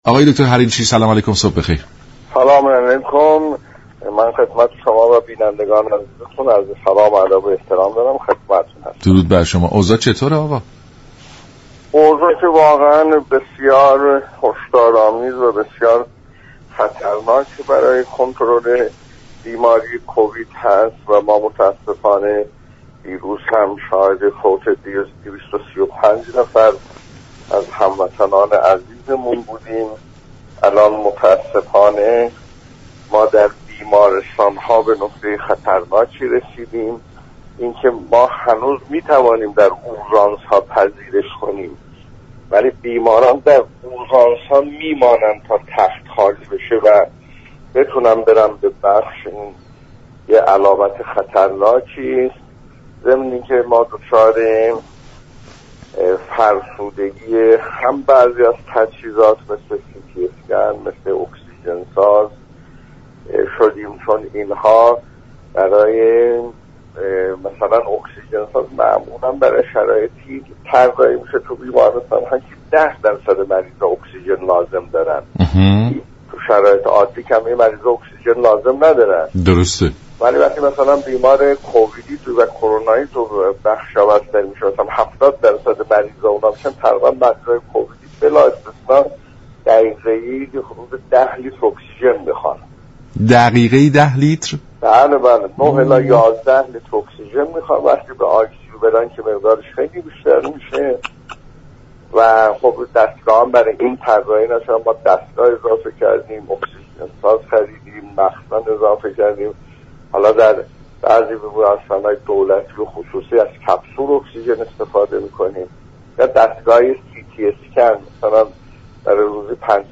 به گزارش شبكه رادیویی ایران، دكتر ایرج حریرچی معاون وزارت بهداشت، درمان و آموزش پزشكی در برنامه «سلام صبح بخیر» با ابراز تاسف از مرگ 235 نفر از هموطنان تا روز گذشته در اثر كرونا، گفت: این روزها كنترل بیماری كووید نوزده در ایران روند خوبی ندارد و این بیماری به مرحله هشدار و خطر رسیده است.